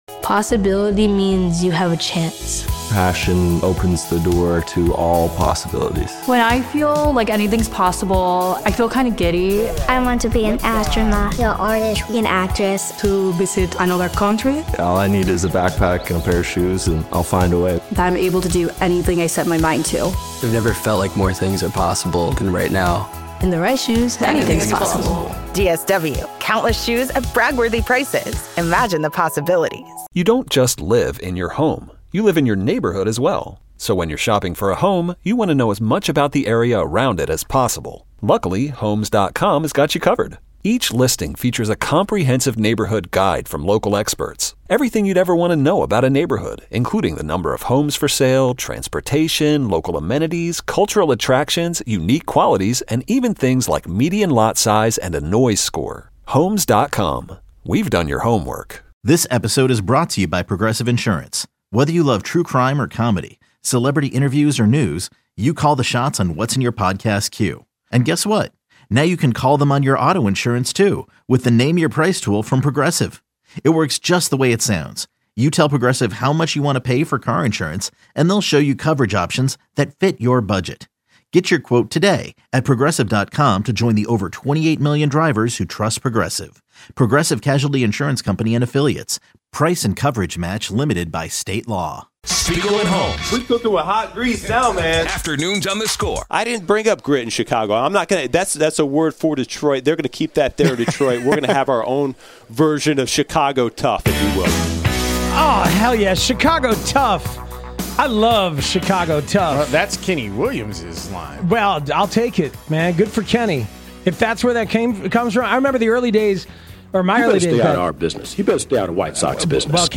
Chicago sports talk